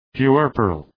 Προφορά
{pju:’ɜ:rpərəl}